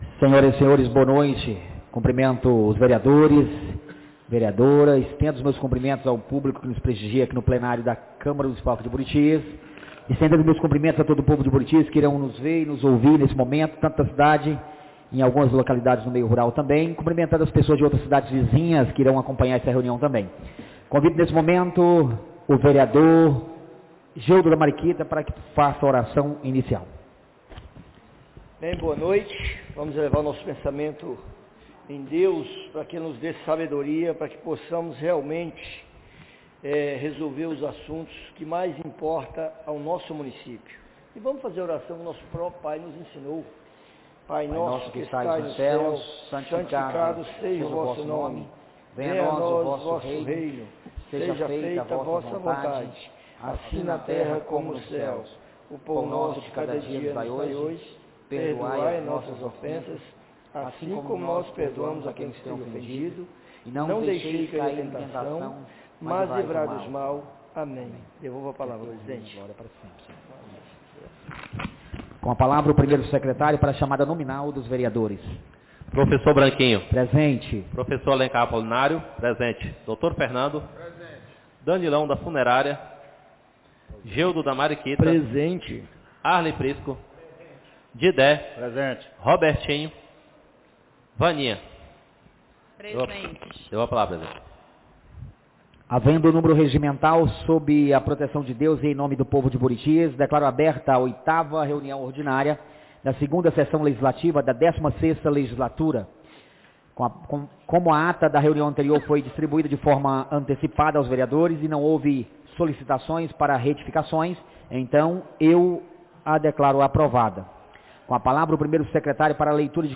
8ª Reunião Ordinária da 2ª Sessão Legislativa da 16ª Legislatura - 23-03-26